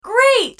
女声great音效_人物音效音效配乐_免费素材下载_提案神器